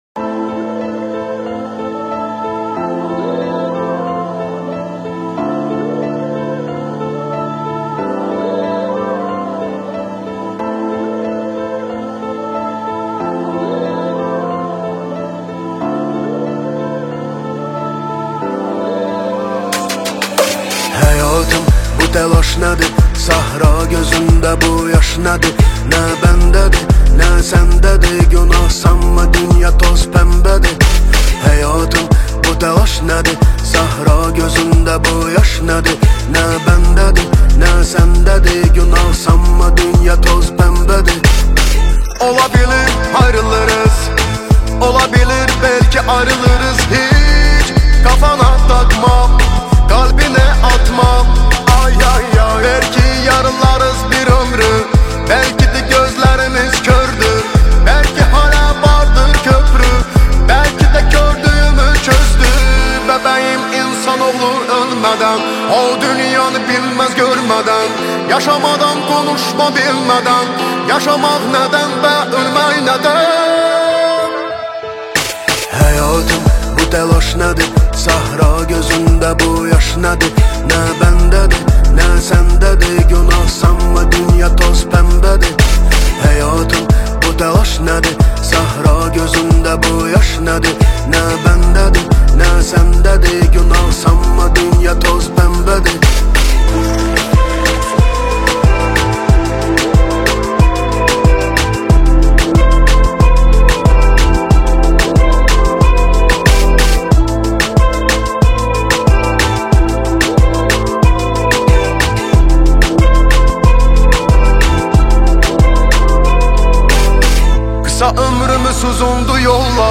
📯Azeri music free download📯